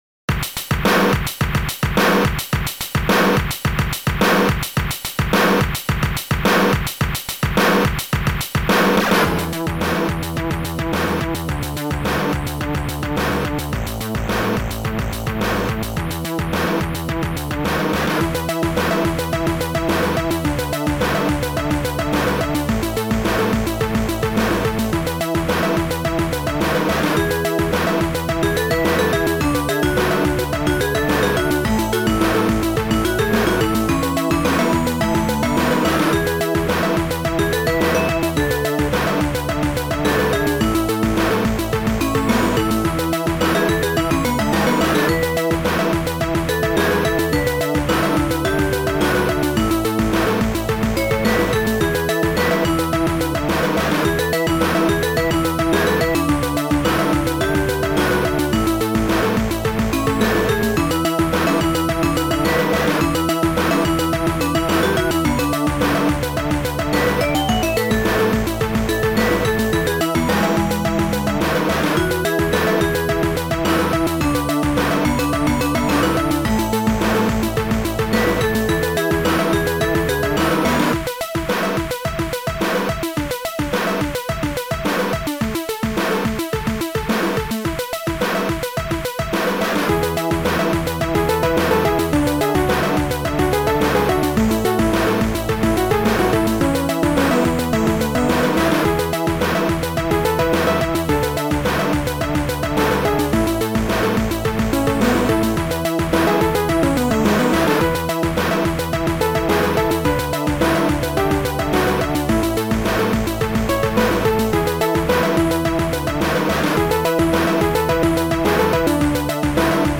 Sound Format: Noisetracker/Protracker
Sound Style: Groovy